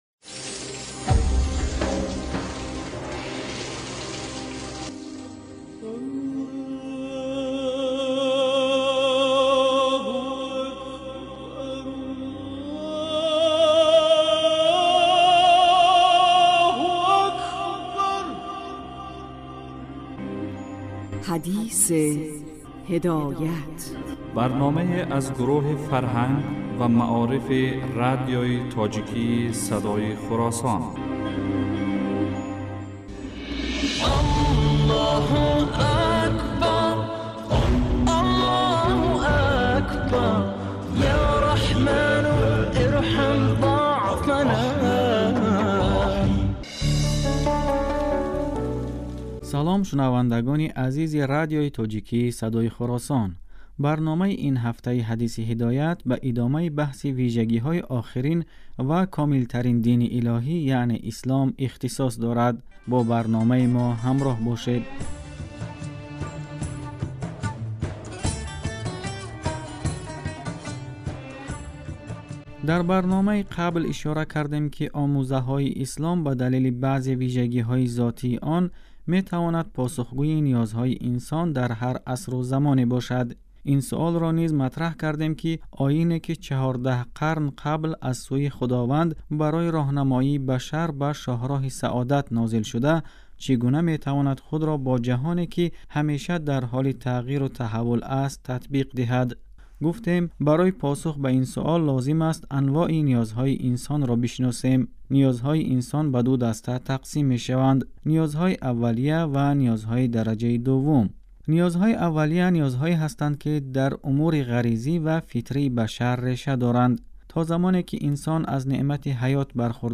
این برنامه پنج شنبه شب ها از صدای خراسان پخش و جمعه در بخش صبحگاهی بازپخش می شود.